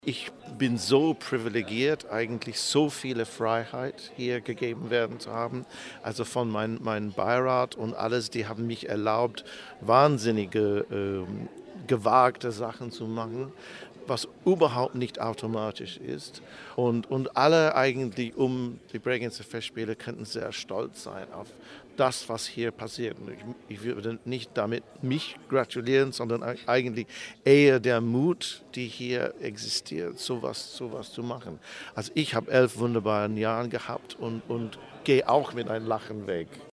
Pressekonferenz Programmpräsentation 2014 - news